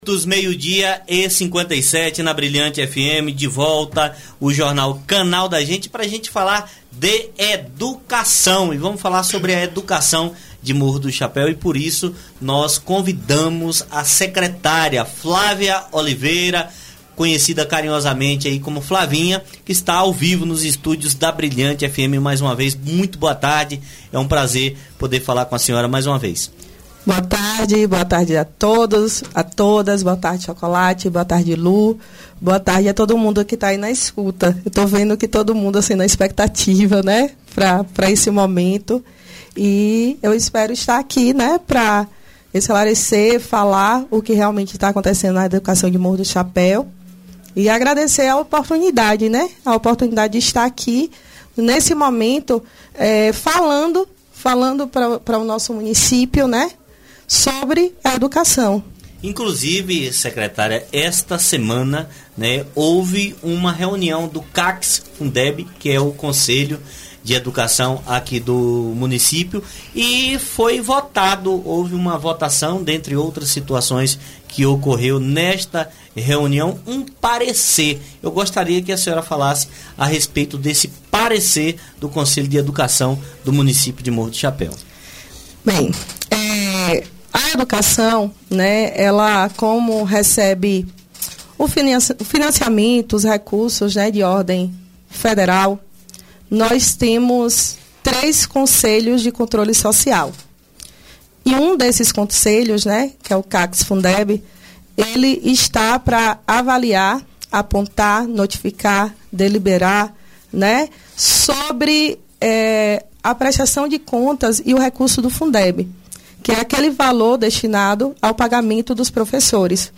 Secretária de educação presta esclarecimentos em entrevista á Radio Brilhante FM – Brilhante Notícias
Após a repercussão de notícias infundadas e distorcidas sobre a Educação de Morro do Chapéu na ultima semana, a secretária de educação do município esteve presente na rádio Brilhante FM para esclarecer e sanar as dúvidas dos morrenses, comprovando o compromisso e a transparência de sua gestão.